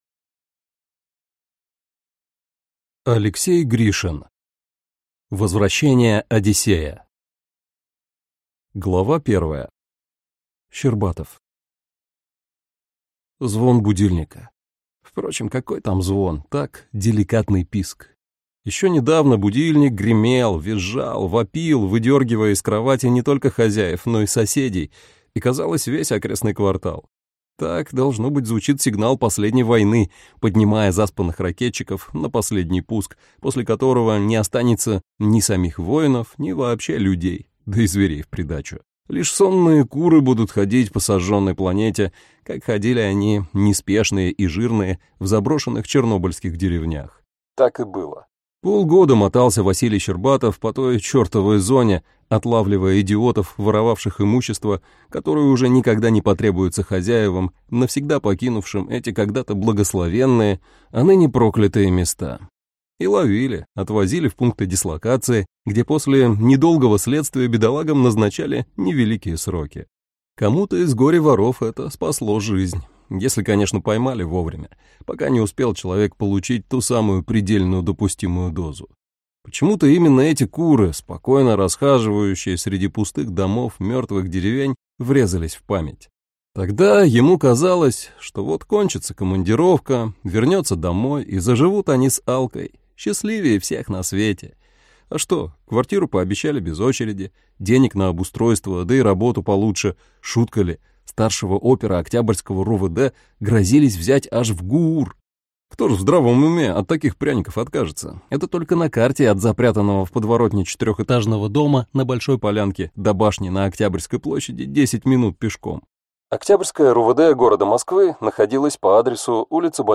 Аудиокнига Возвращение «Одиссея» | Библиотека аудиокниг